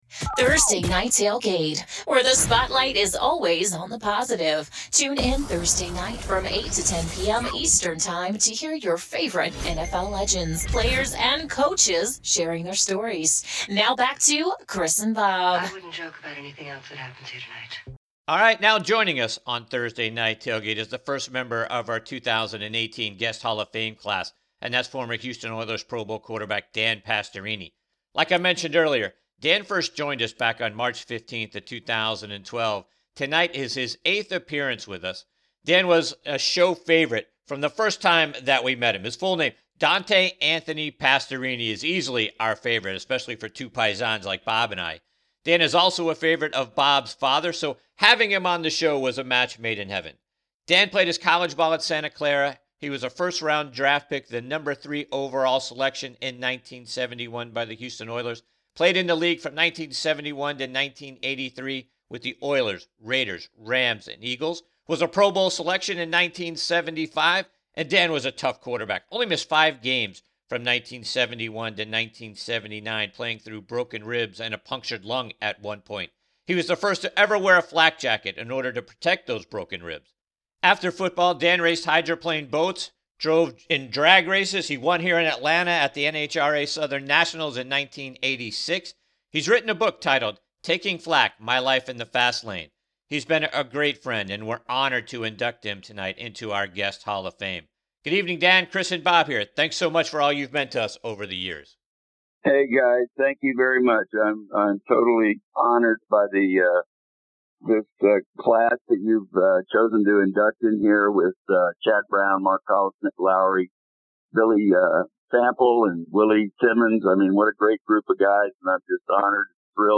Former Houston Oilers Pro Bowl QB Dan Pastorini joins us on this segment of Thursday Night Tailgate NFL Podcast.